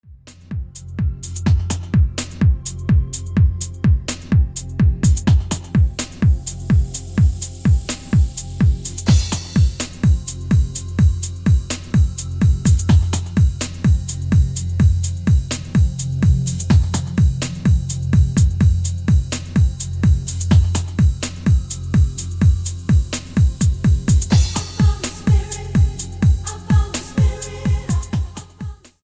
STYLE: Dance/Electronic